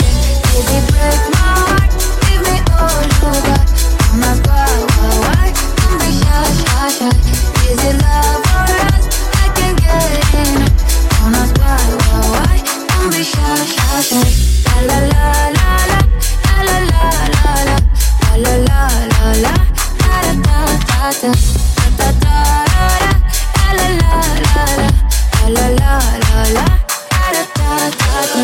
Genere: house,deep,edm,remix,hit